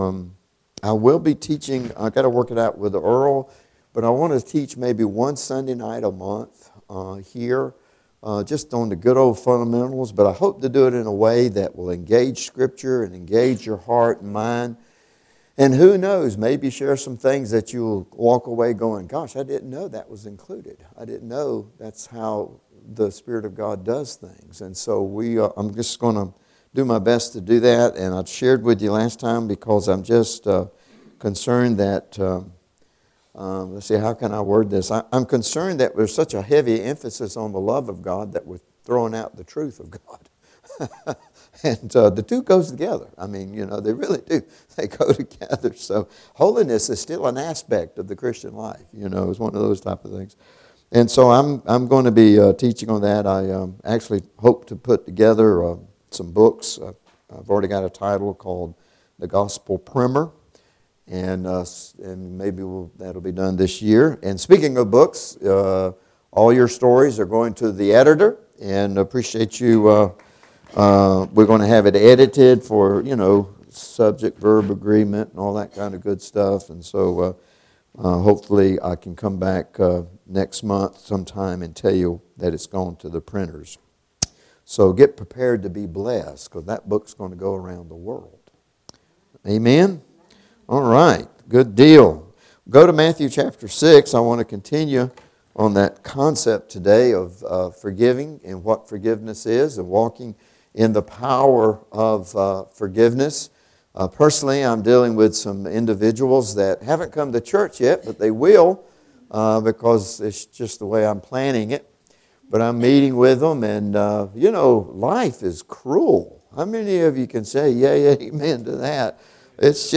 Christ Community Training Center Sermons